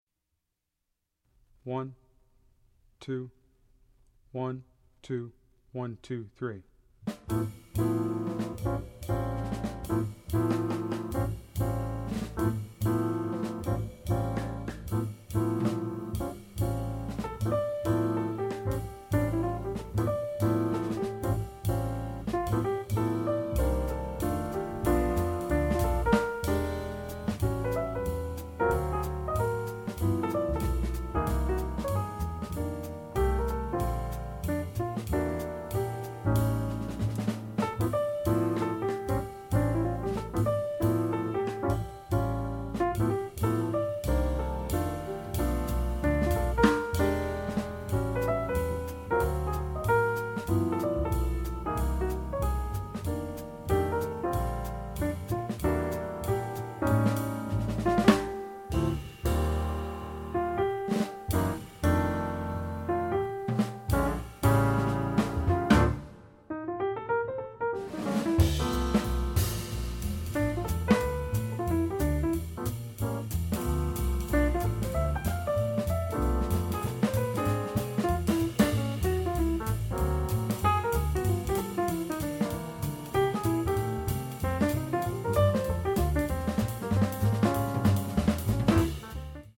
Voicing: Flute w/ Audio